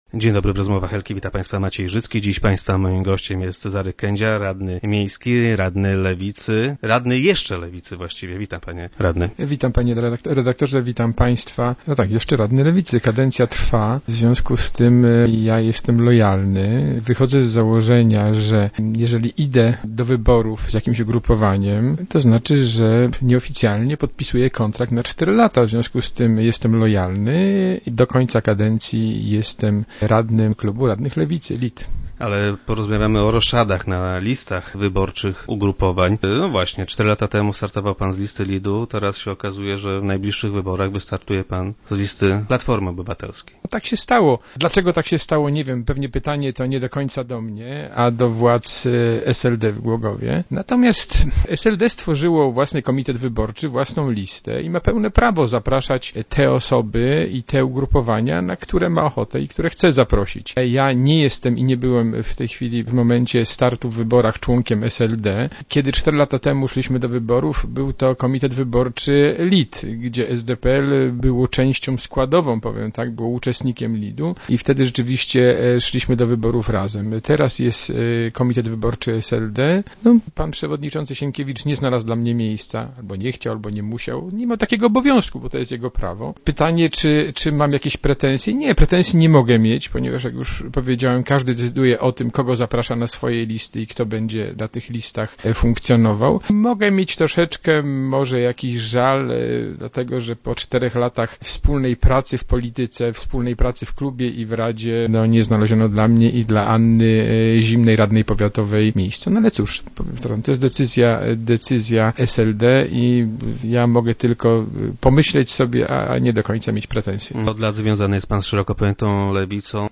Cezary Kędzia, głogowski radny lewicy, wystartuje w zbliżających się wyborach z listy PO. Dlaczego nie po drodze mu z lewicą? Cezary Kędzia był gościem poniedziałkowych Rozmów Elki.